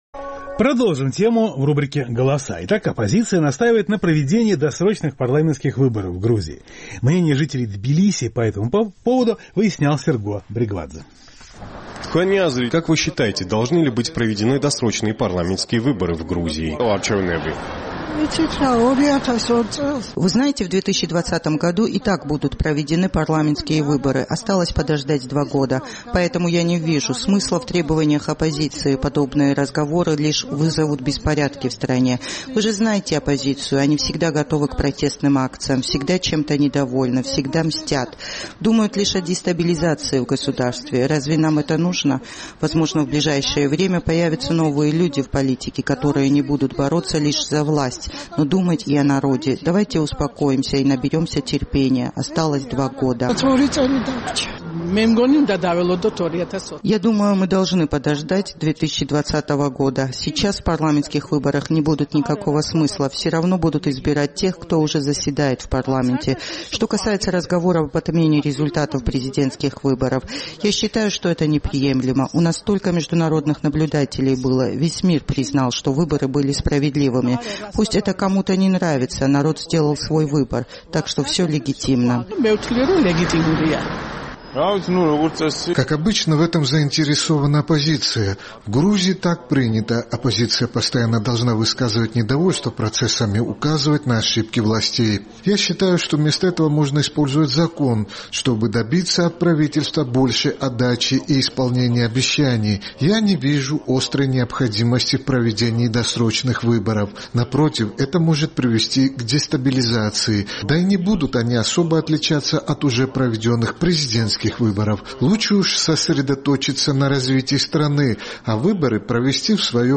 Оппозиция настаивает на проведении досрочных парламентских выборов в Грузии. Мнение горожан по этому вопросу выяснял наш тбилисский корреспондент.